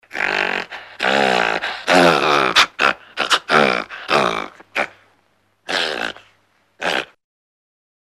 4 gorille.mp3